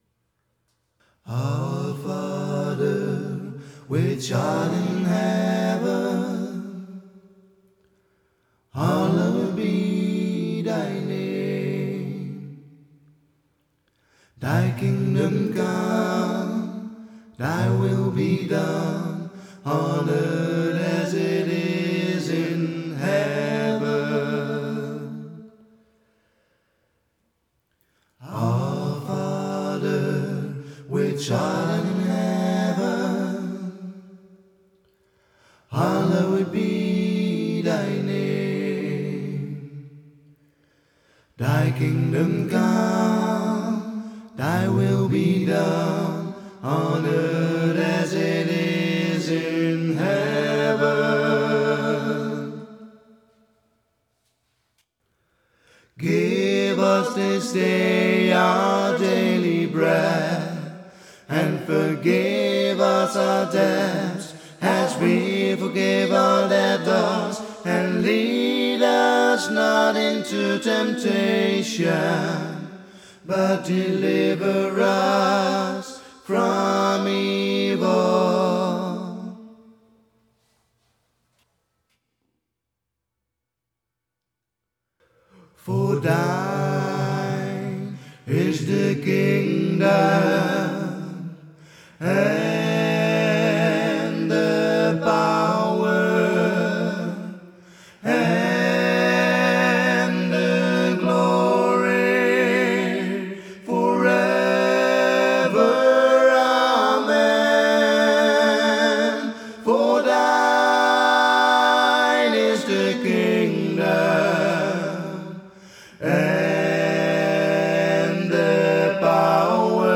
A cappella (WAV) (no instrumental, only two voices)